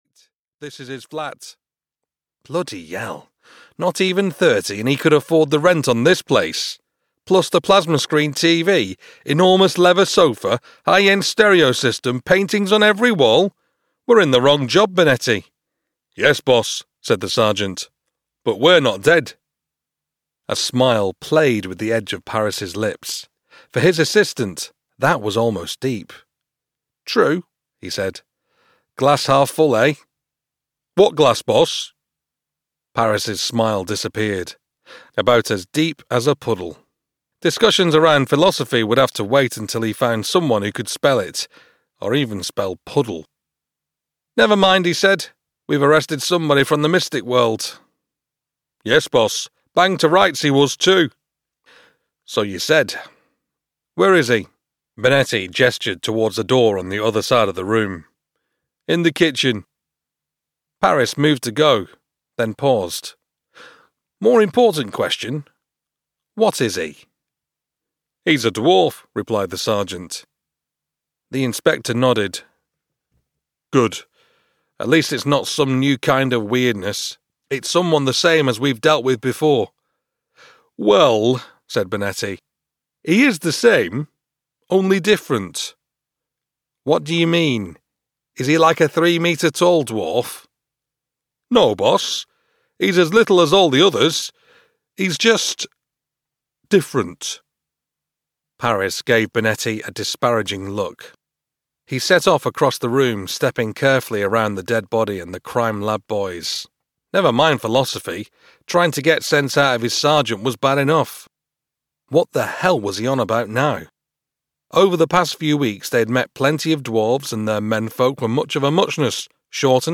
Know Your Rites (EN) audiokniha
Ukázka z knihy